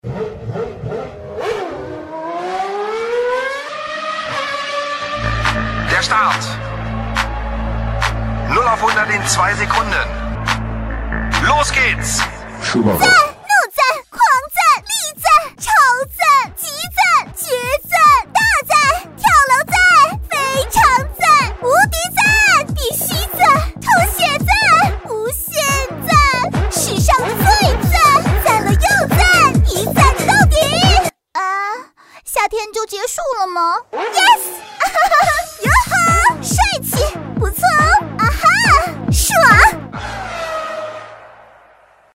女国150_动画_游戏_很燃的赛车游戏音-燃烧吧小少女.mp3